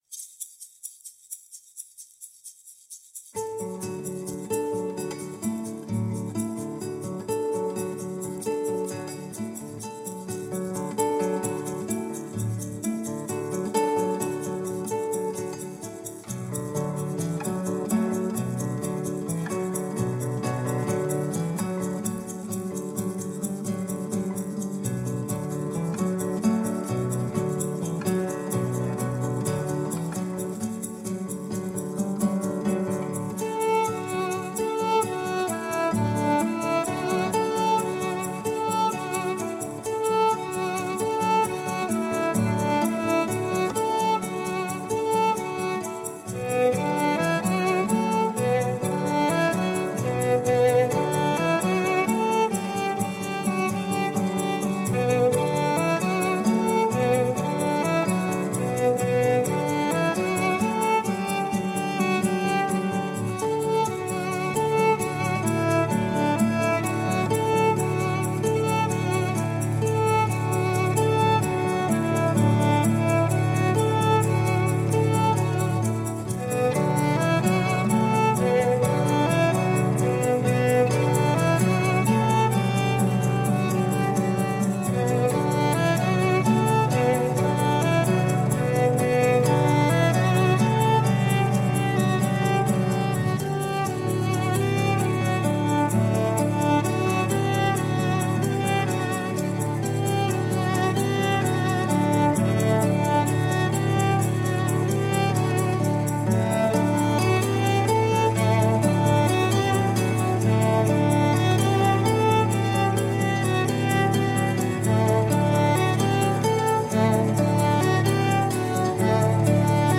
Early music meets global folk at the penguin café.
Tagged as: World, Folk-Rock